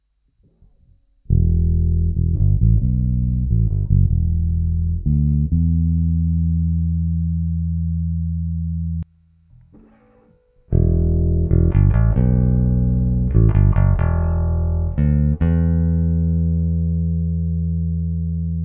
Pickup heights on both basses are the same. All recording levels and vol pots etc are the same.
Clips are treble rolled off then on. As thread concerns Precision pickups only the split-P was recorded.